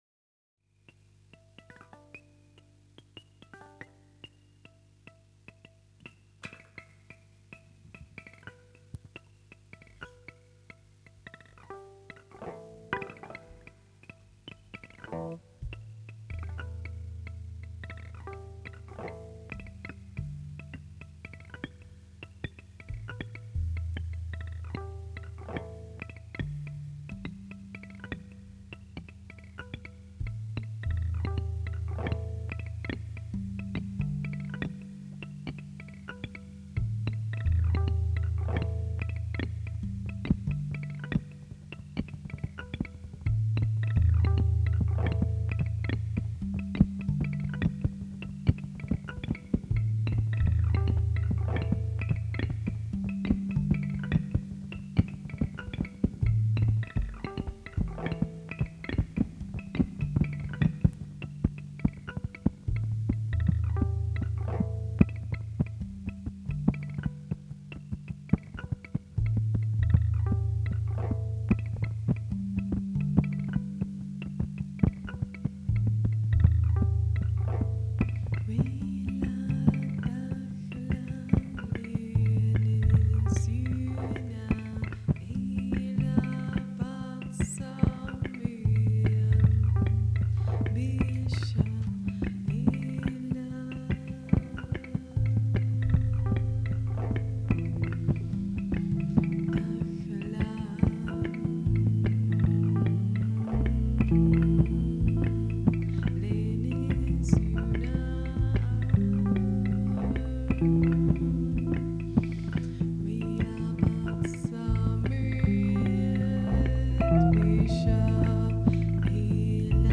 ambientní etno-jazz s texty aramejského otčenáše